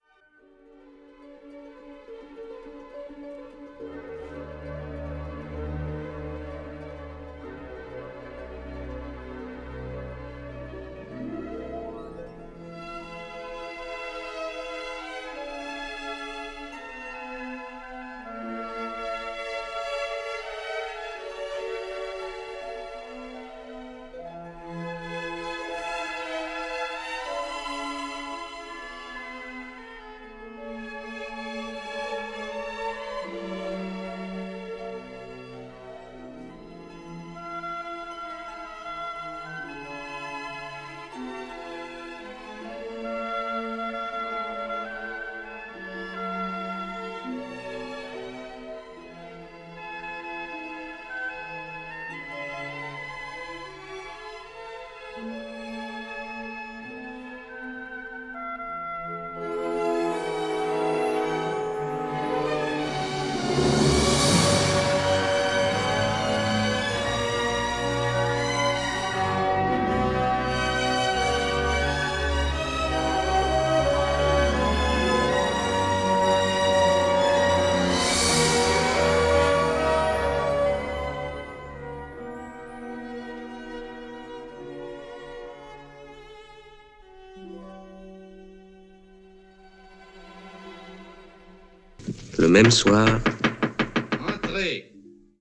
Narrateur : 19 ans de bagne
09f12-2-narrateur.mp3